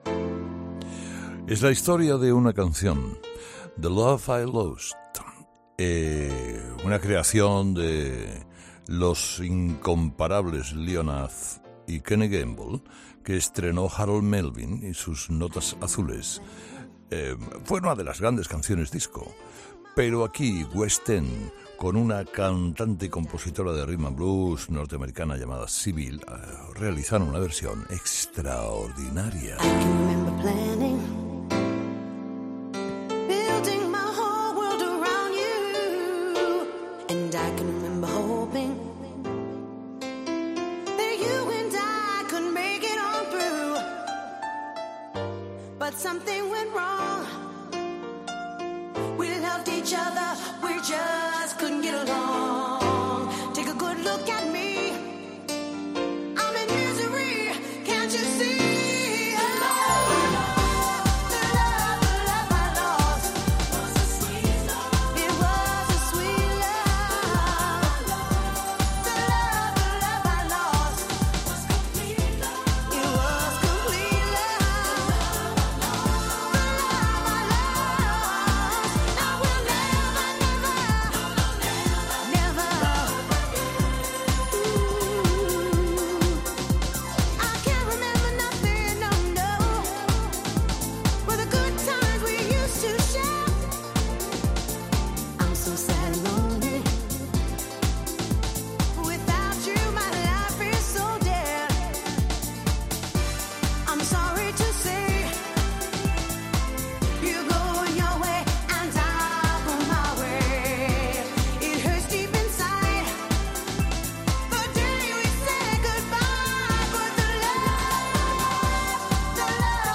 una cantante compositora de Rhythm and blues, norteamericana